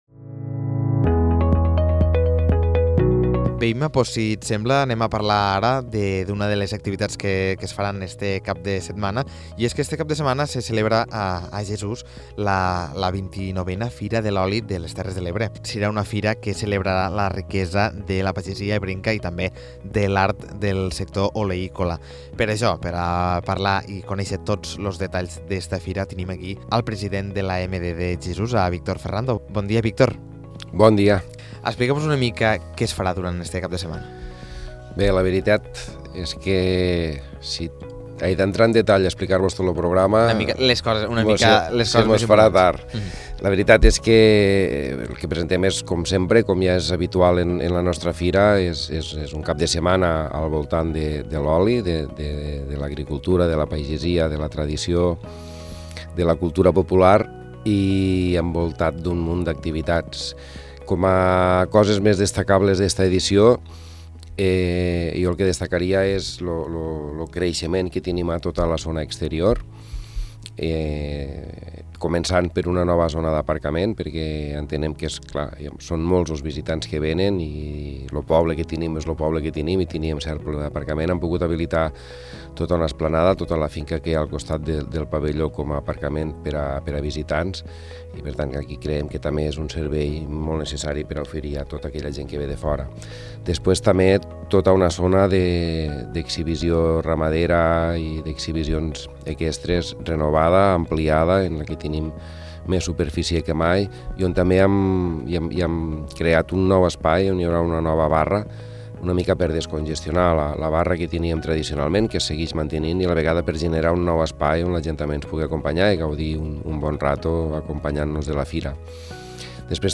Víctor Ferrando, president de l’EMD de Jesús, ens explica què trobarem els dies 11 i 12 de maig en aquesta edició d’una fira que està més que consolidada i arriba a la seva 29a edició.